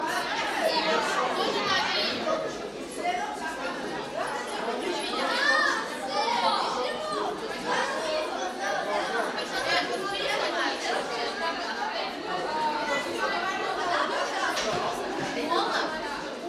Звуки школьной перемены скачать бесплатно.
Звук школьной перемены
Шум, гам, крики, топот, стук и прочее-прочее!